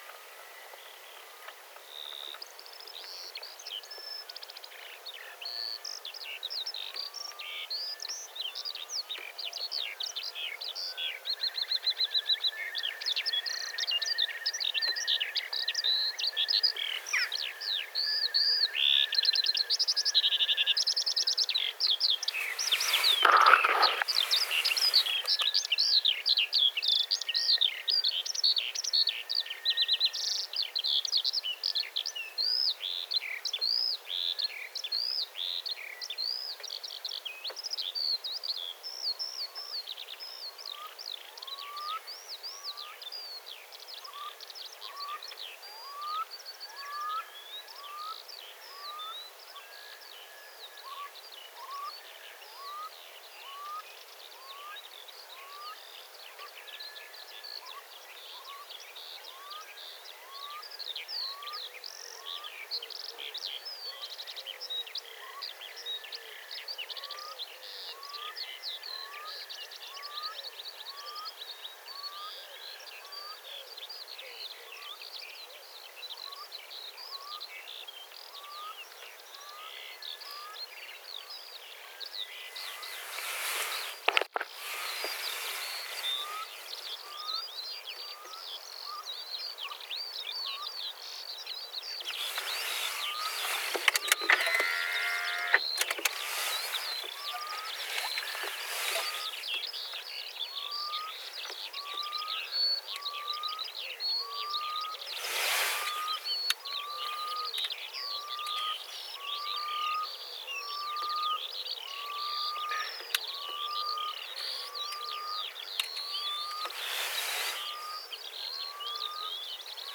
kiuru laulaa, kuovien kevätääntelyjä
kiuru_laulaa_kuovien_kevataantelya.mp3